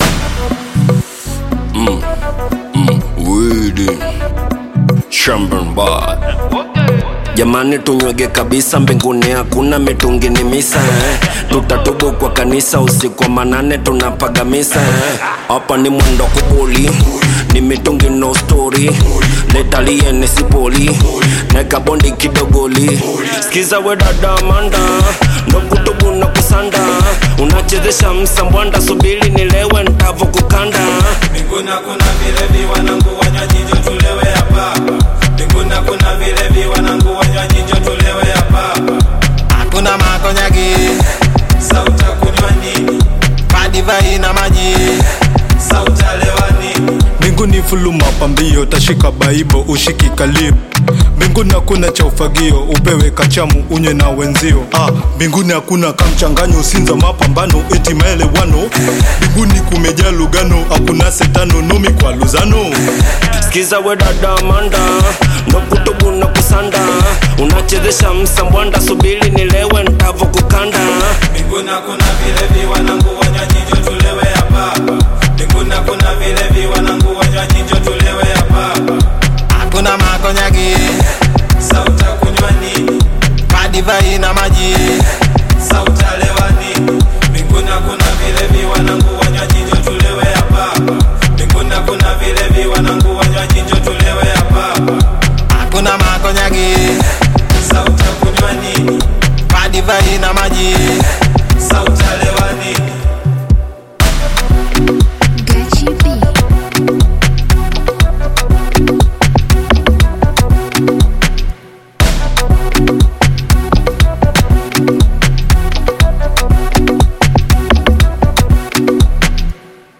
Bongo Flava music track
Tanzanian Bongo Flava artist, singer and songwriter
Bongo Flava song